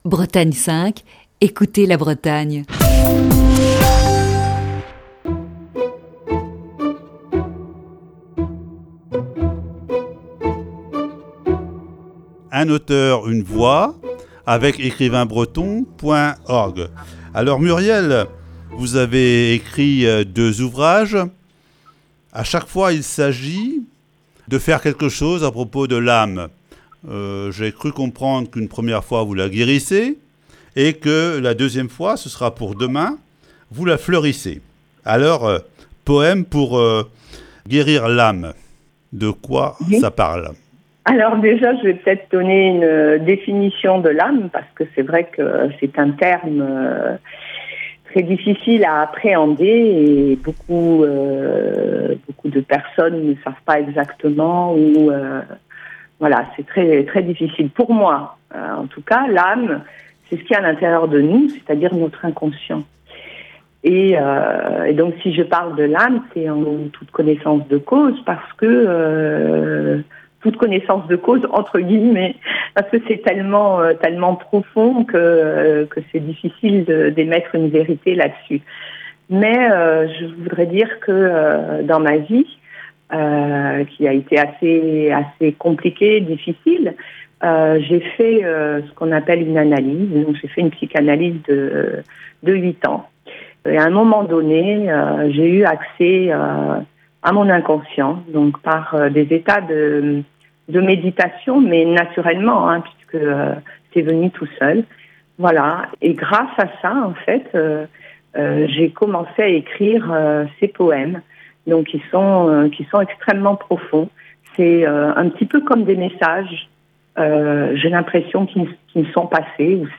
Chronique du 25 juin 2020.